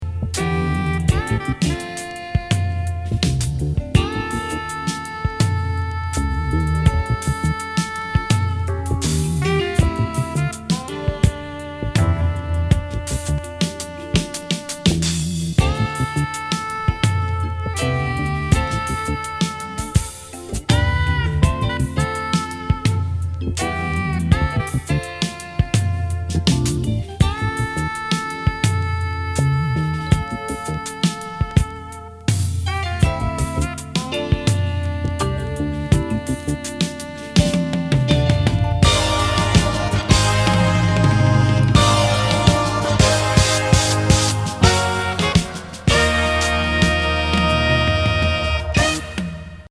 シンセ・スペーシーなトロピカル・メロウ・フュージョン